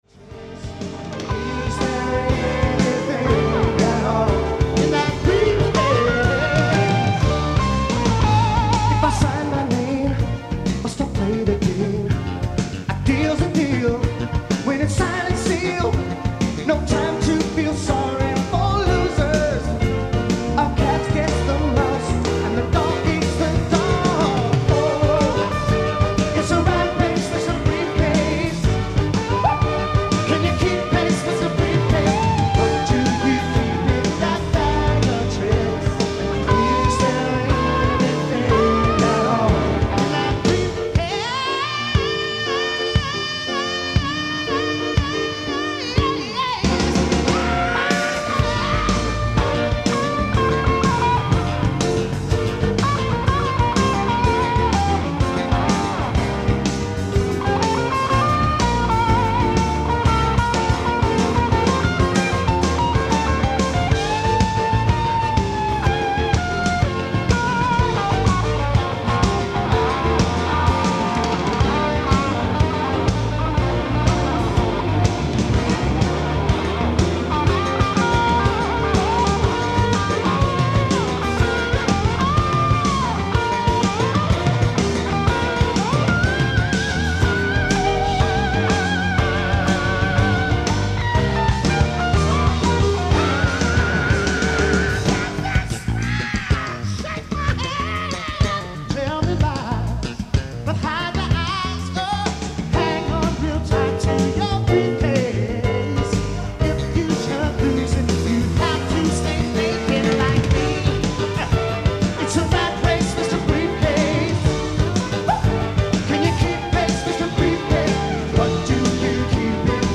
ライブ・アット・人見記念講堂、東京 05/04/1987
※試聴用に実際より音質を落としています。